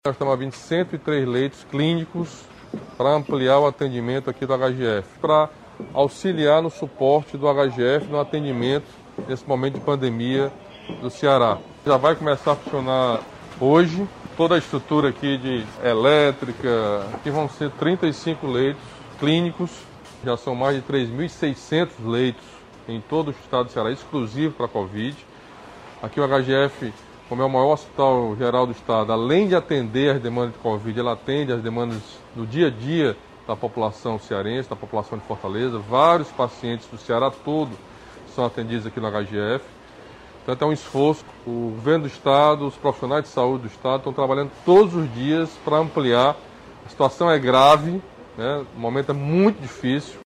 O governador Camilo Santana destacou a importância dos novos leitos no HGF para o enfrentamento à pandemia.